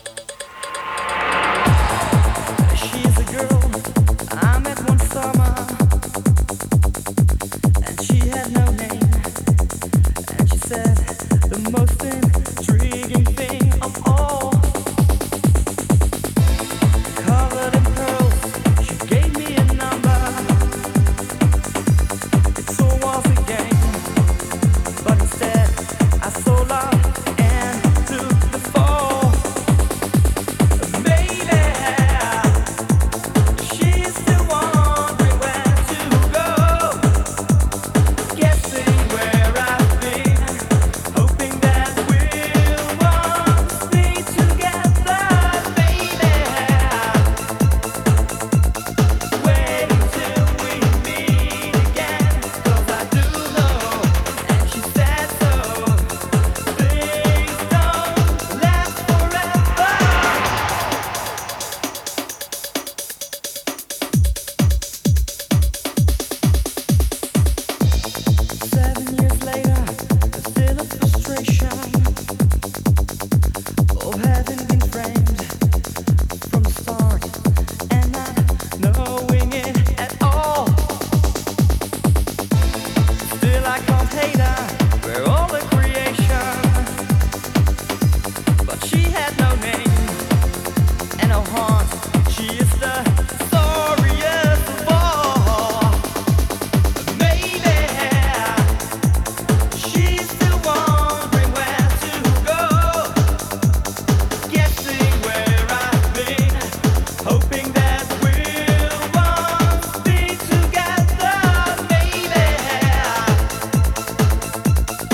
vocal space synth, vocoders, Laser Dance
vintage synth-driven music
Disco Italo